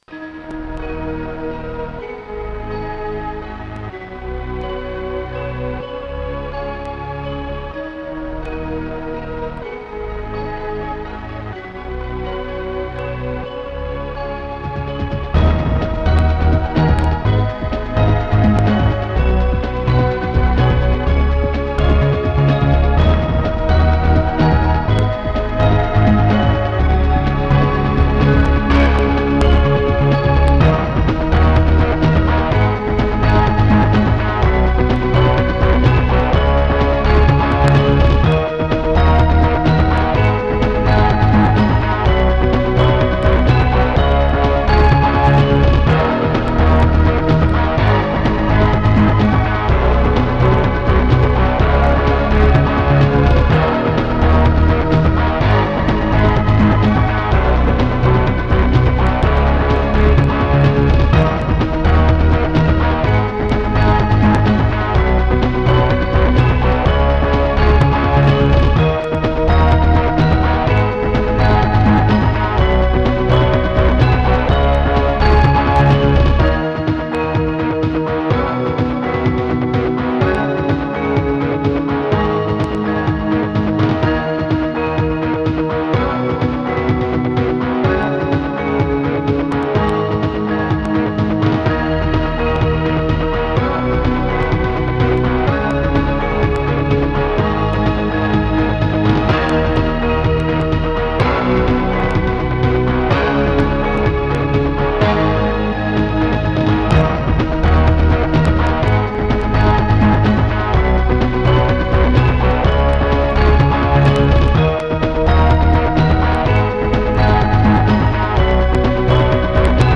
Music: tracker 8-channel .s3m
AZTech SoundGalaxy BX II